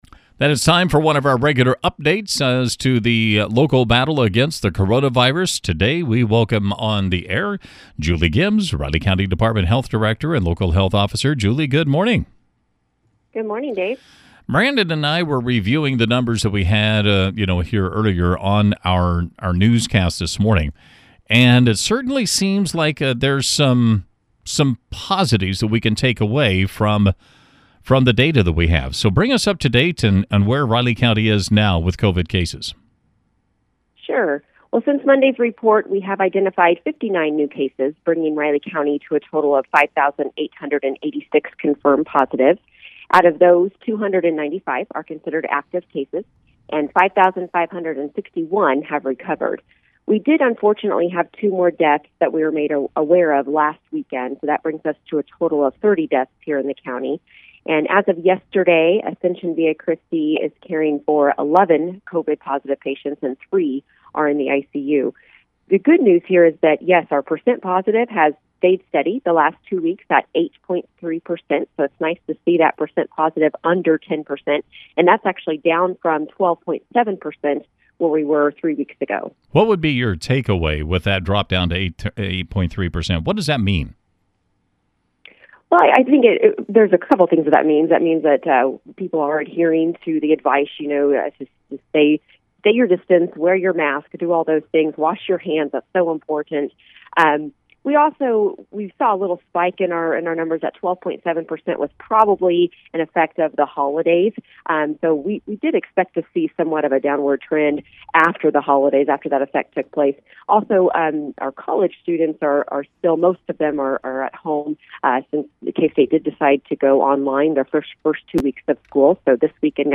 1/28/21 - COVID-19 update with Riley Co. Health Officer Julie Gibbs